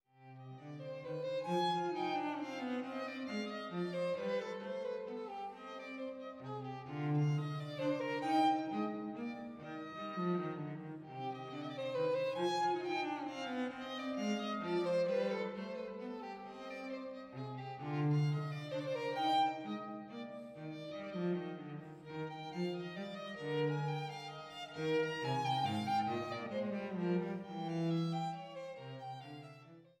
Violine
Violincello